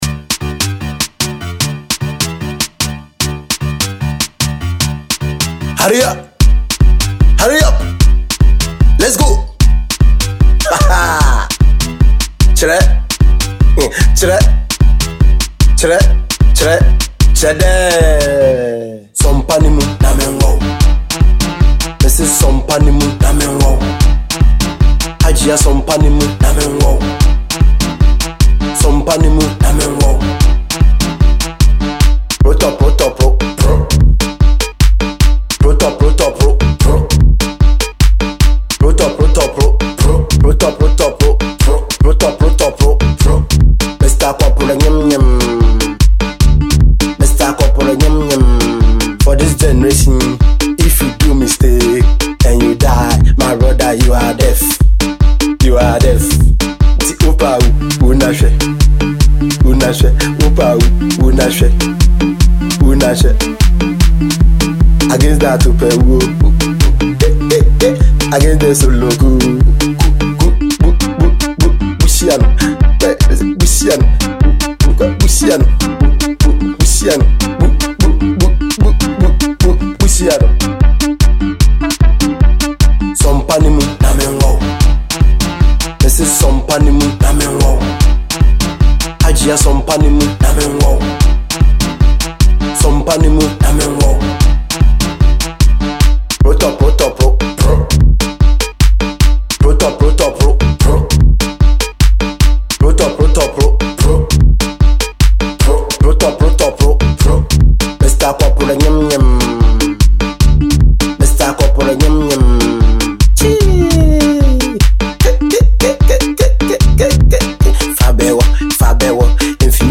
and this is a danceable tune for all.